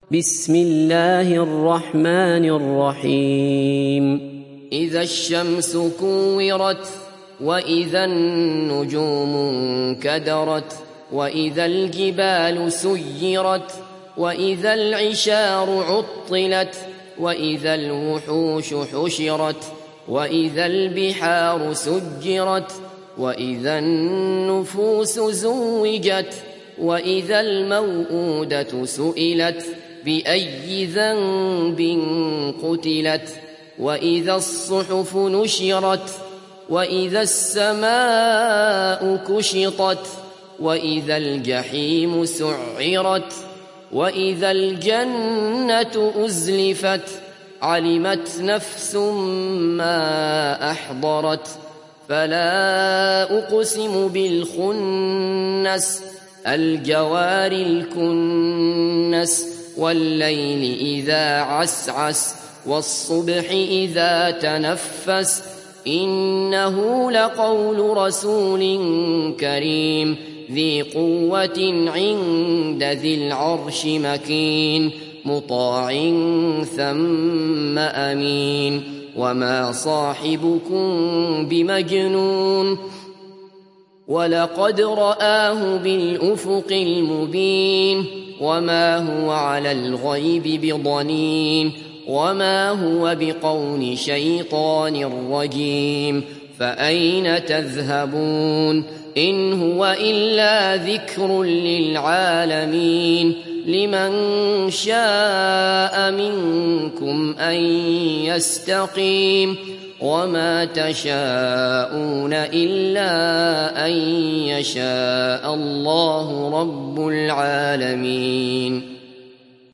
تحميل سورة التكوير mp3 بصوت عبد الله بصفر برواية حفص عن عاصم, تحميل استماع القرآن الكريم على الجوال mp3 كاملا بروابط مباشرة وسريعة